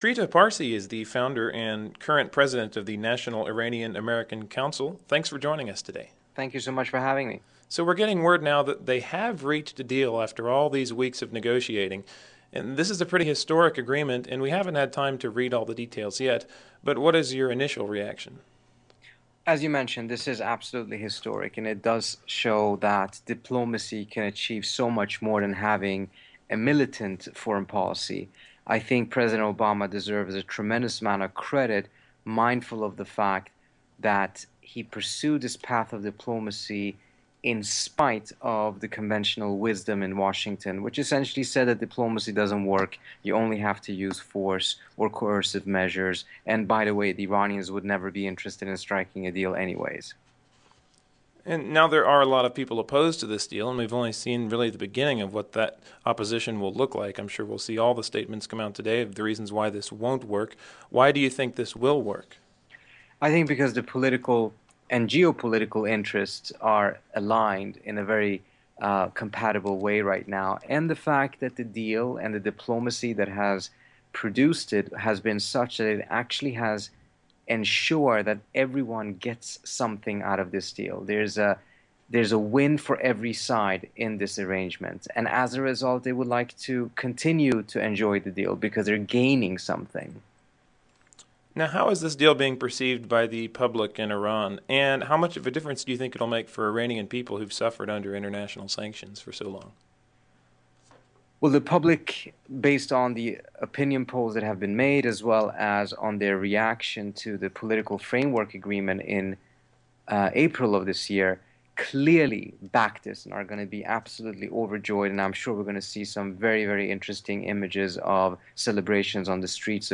Interview with Trita Parsi, the president of the National Iranian American Council